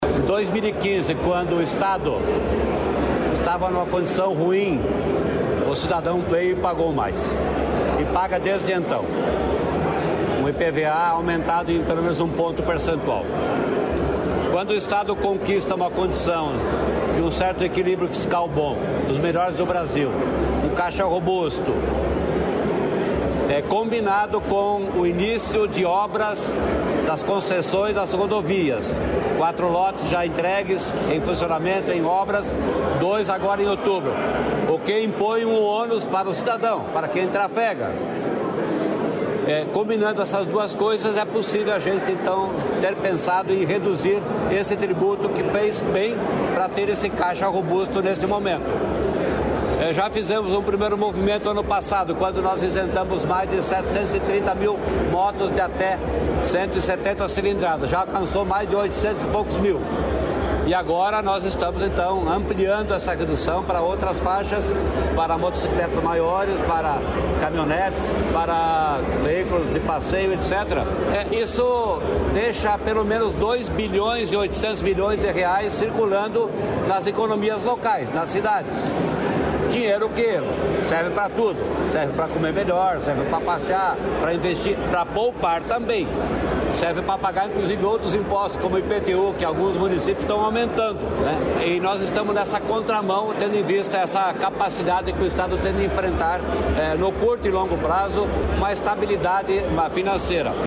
Sonora do secretário da fazenda, Norberto Ortigara, sobre a redução do IPVA